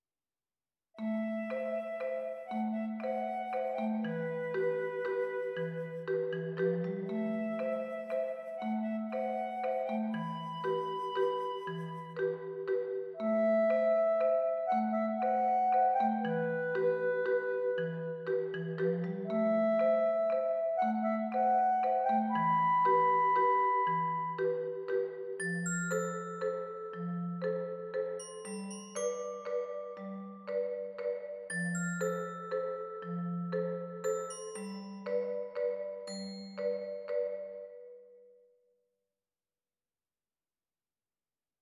family MIDI mock-up
Here’s a very basic MIDI mock-up the main theme of “family”, the piece for the Gun Room mentioned in my last blog post. It needs a contrasting section still, but it’s a nice start.